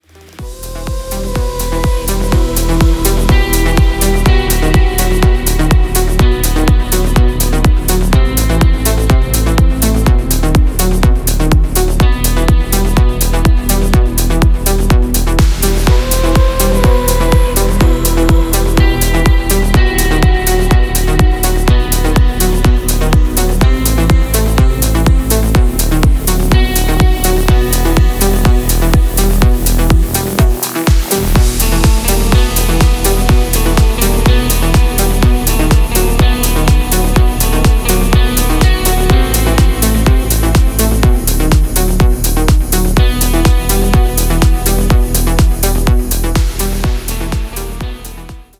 • Качество: 320, Stereo
Стиль: deep house, nu disco.